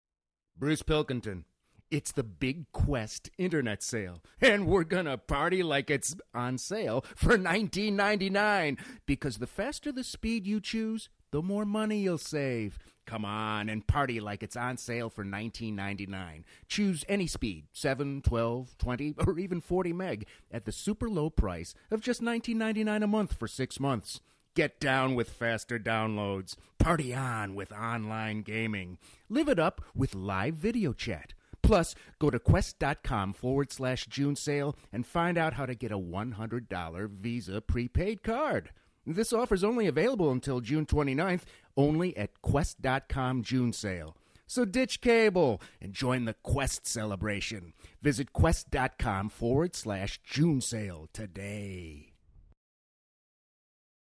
INSTINCTIVE CHOICES CHARACTER VOICES commercials,industrials: versatile, here to please the client! From real-person read to zany character voice
Sprechprobe: Sonstiges (Muttersprache):
Mid-range, can do serious PSA, warm real-person, or zany character!